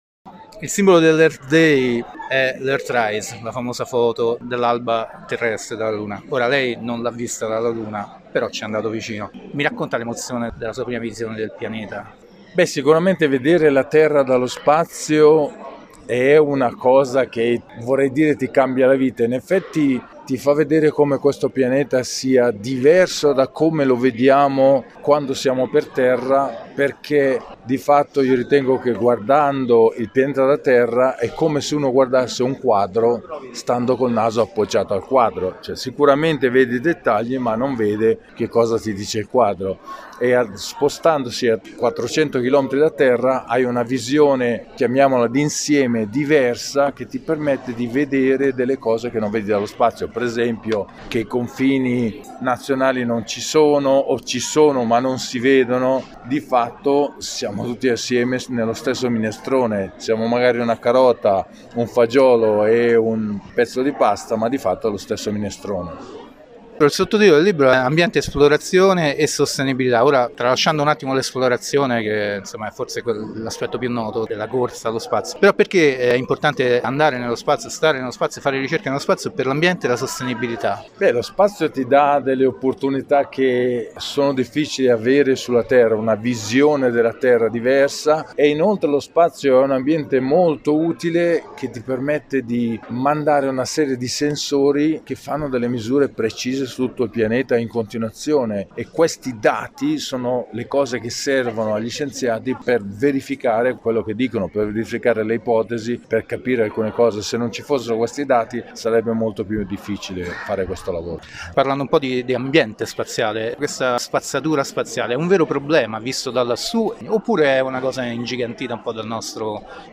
Cooperazione internazionale, ricerca, satelliti, rilevamenti ambientali, indagini sul cambiamento climatico, detriti orbitanti. Intervista a Paolo Nespoli sulla sostenibilità del settore aerospaziale, in occasione della presentazione del libro “Spazio – La sfida del presente” di David Brown (Hoepli Editore).
Di seguito il podcast dell’intervista.